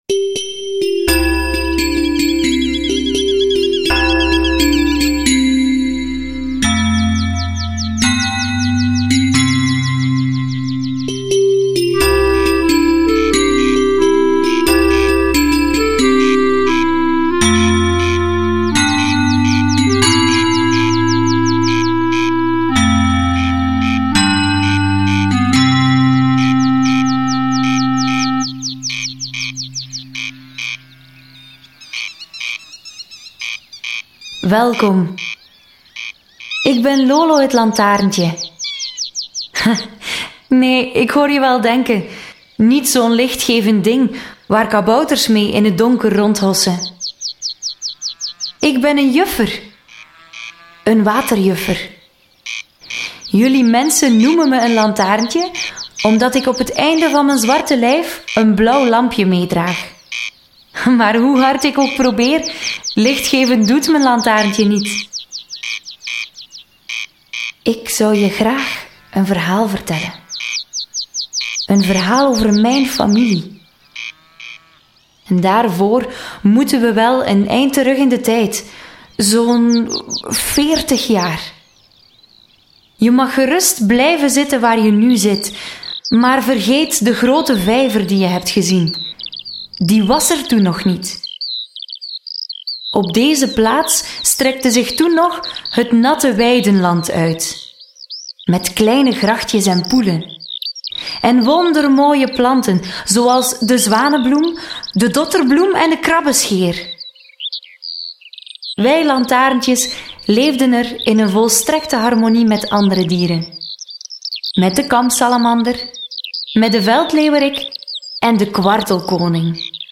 In één van de ‘zitdruppels’ worden kinderen meegenomen door het verhaal van Lolo de waterjuffer en het ontstaan van De Gavers. Je kan het luisterverhaal beluisteren en downloaden via deze link .